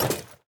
Equip_netherite3.ogg.mp3